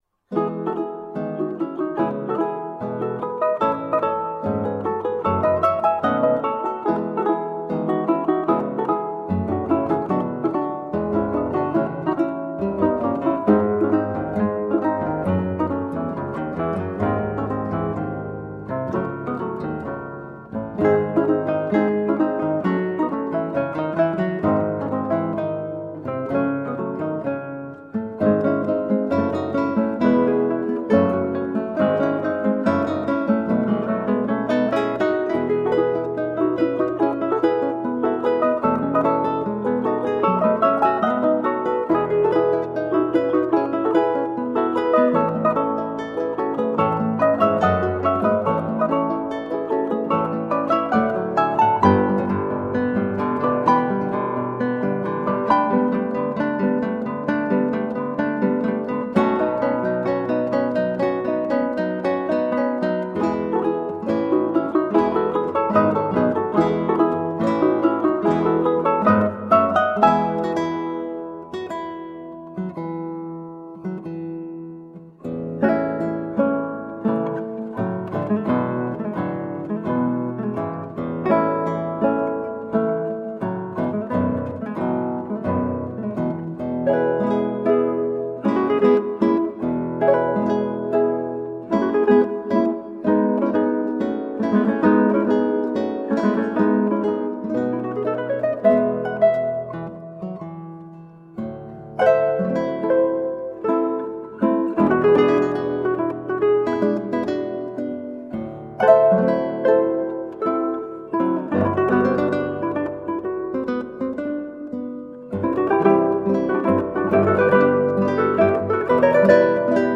Colorful classical guitar.
Classical, Impressionism, Instrumental
Classical Guitar, Ukulele